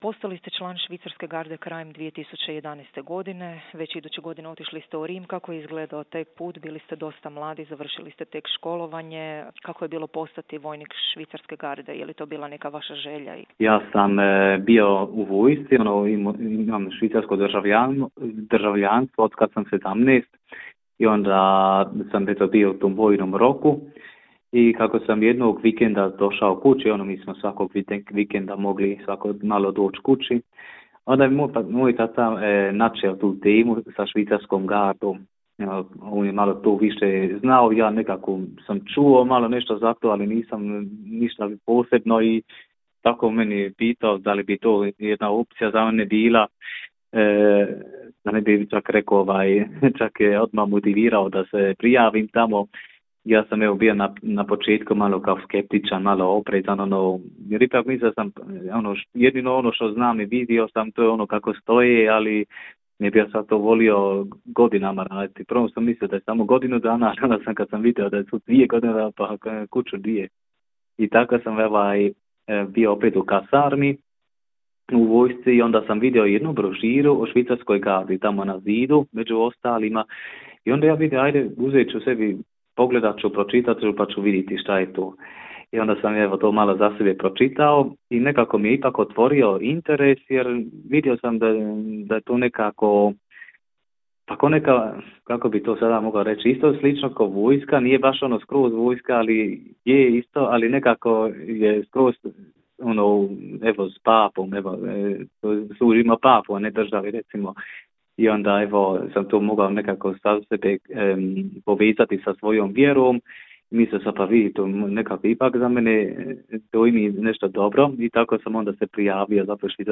Cijeli intervju